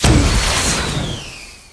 l_missile_launch.wav